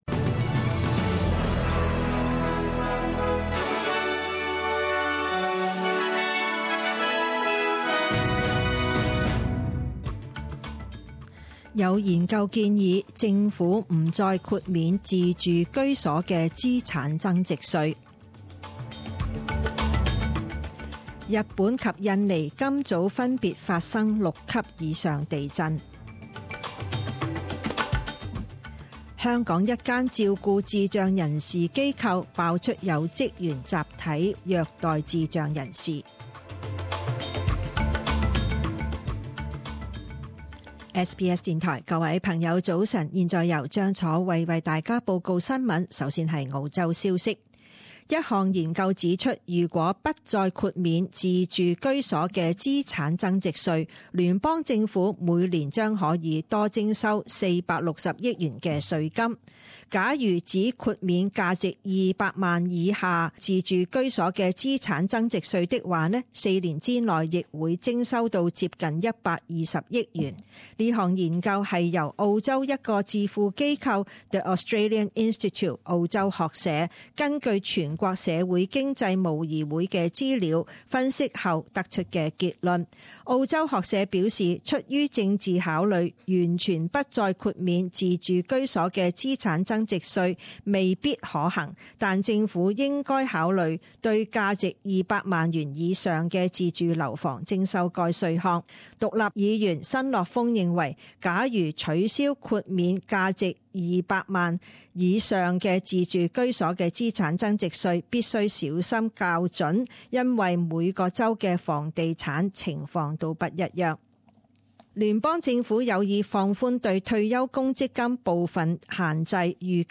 一月十二日十点钟新闻报导